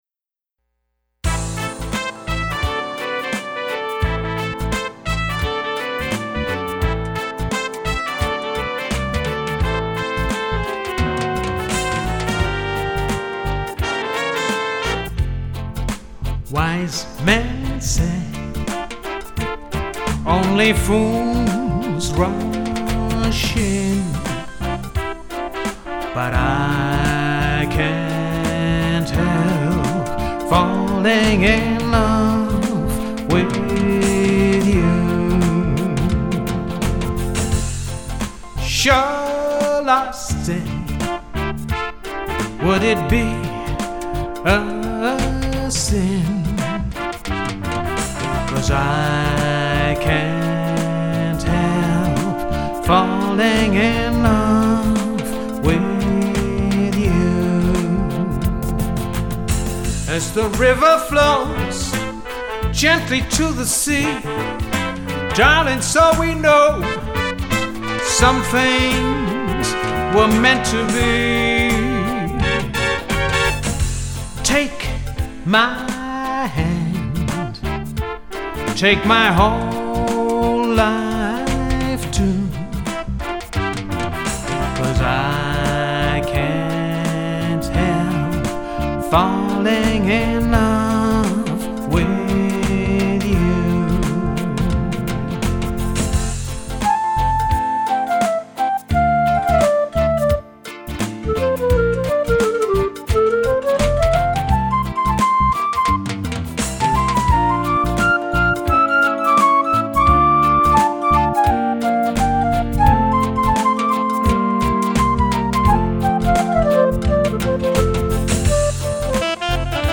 70’s & 80’s / Party Pop / Reggae / Hot Latin / Disco